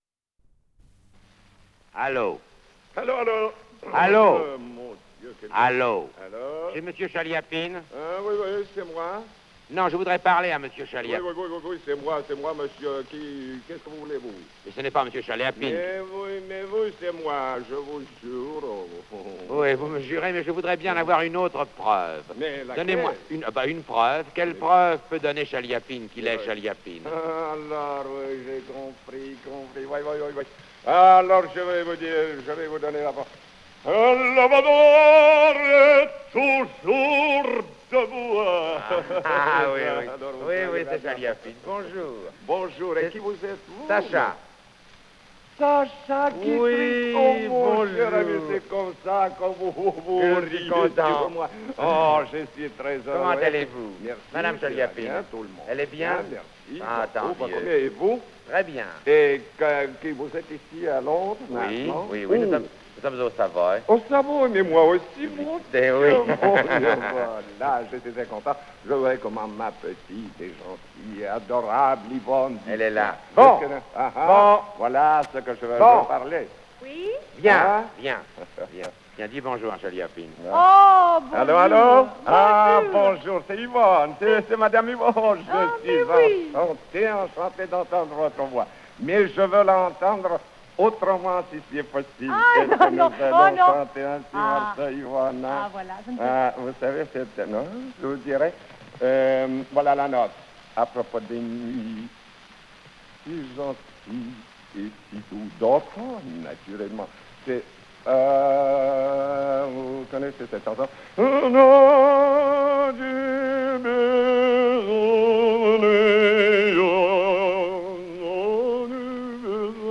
Название передачиШуточный телефонный разговор
ПодзаголовокСкетч, на французском языке
Скорость ленты38 см/с
ВариантДубль моно